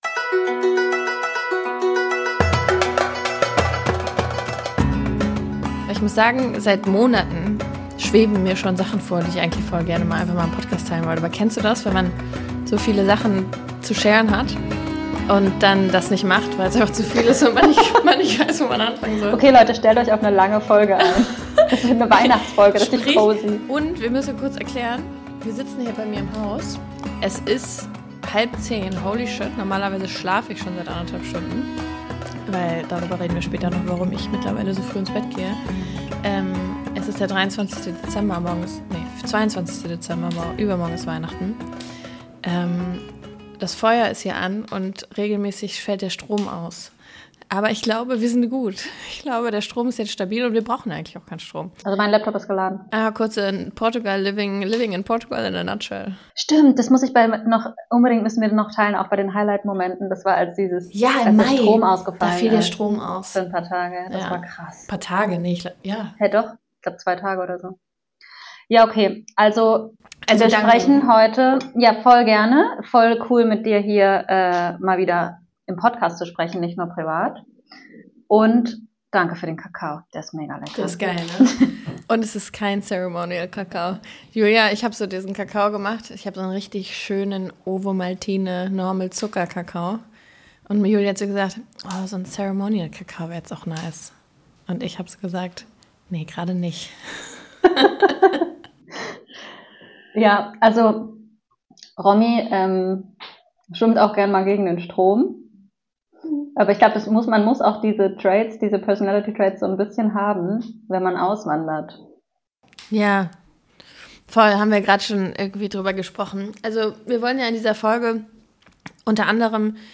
Persönlich, ehrlich und mit einem Augenzwinkern plaudern wir aus dem Nähkästchen.